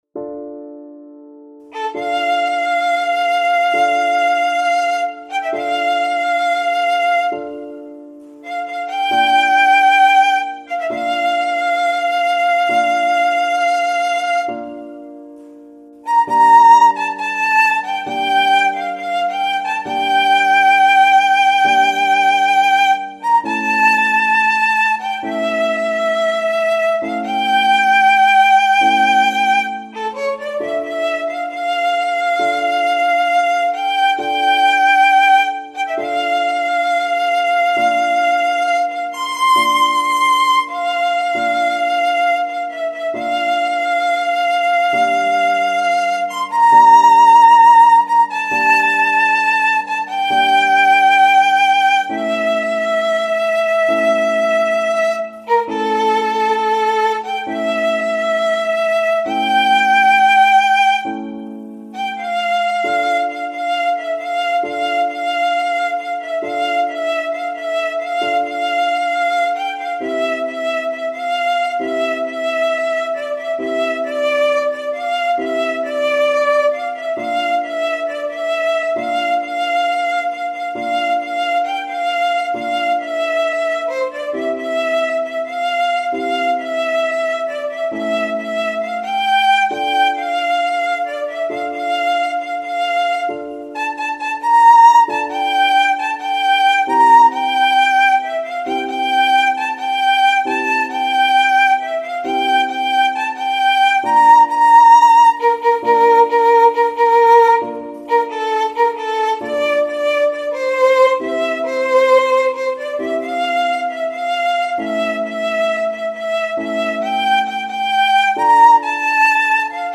ویولون